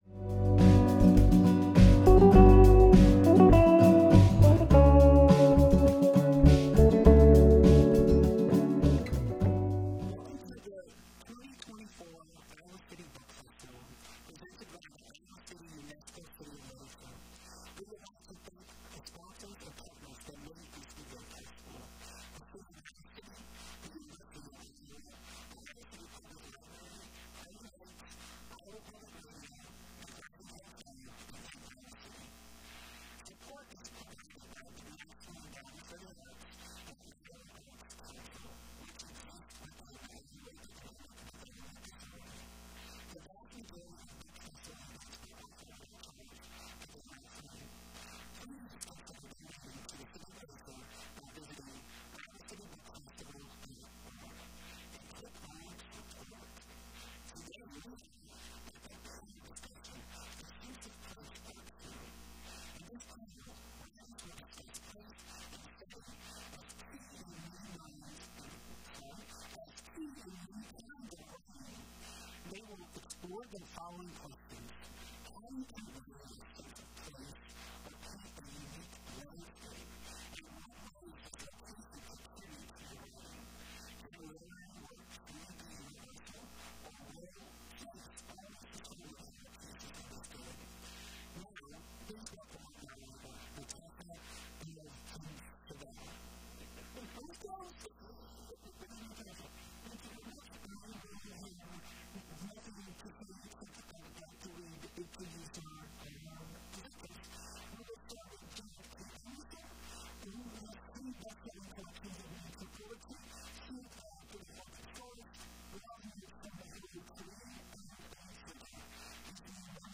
Iowa City UNESCO City of Literature presents a panel discussion from its 2024 Iowa City Book Festival.